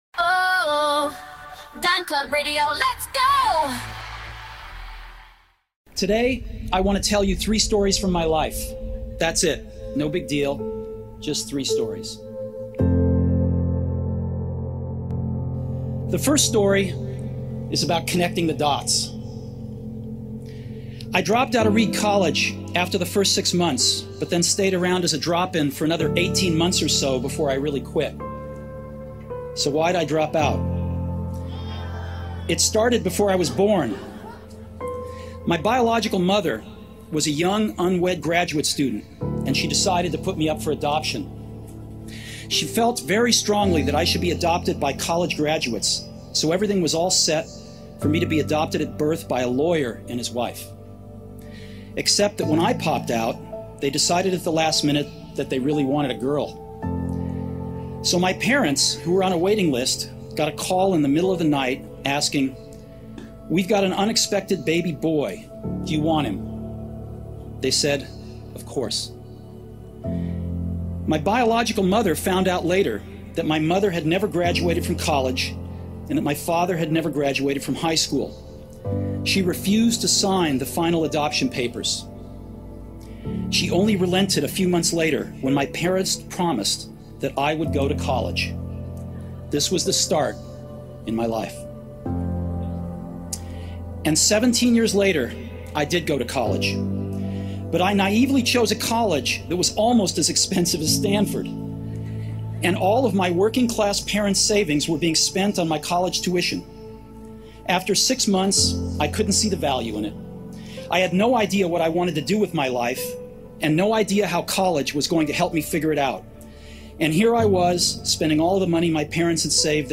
Steve Jobs delivers an inspirational speech.